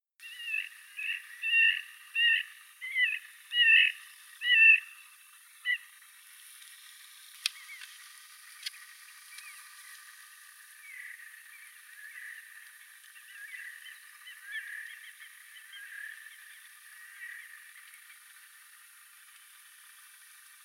tupsu-uikun soidinääntelyä,
pikkulokkimaista ääntelyä
Voisiko tuo pikkulokkimainen ääntely,
olla ehkäpä naaraan ääntelyä?
tupsu-uikun_soidinaantelya_en_tieda_onko_tuo_pikkulokkimainen_aantely_ehkapa_naaraan_aantelya.mp3